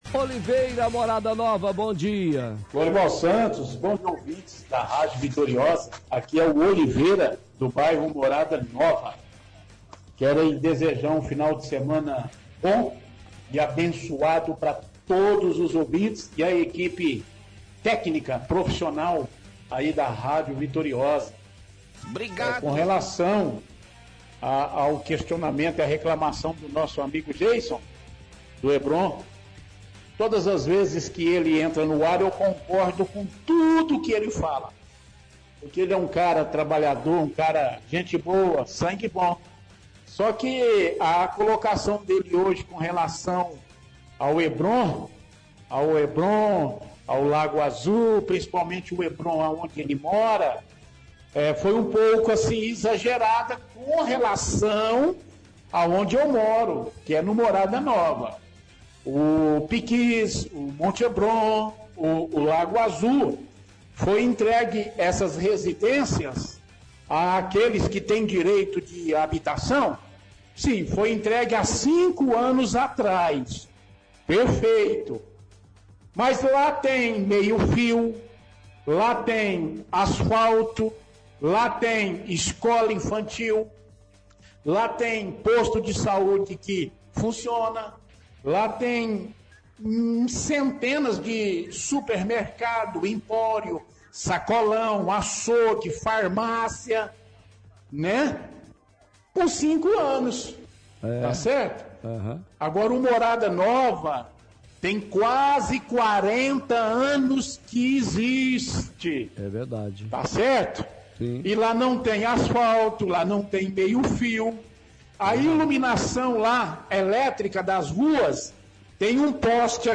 – Ouvinte reclama de falta de estrutura no bairro Morada Nova que existe a quase 40 anos e não tem asfalto, não tem meio fio e tem poucos postes de energia